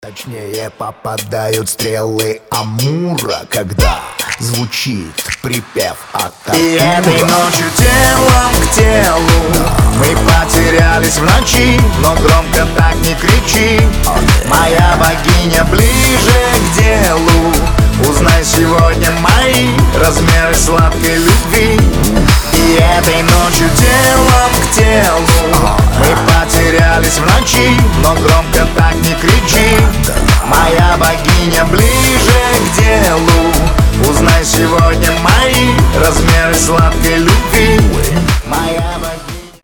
• Качество: 320, Stereo
попса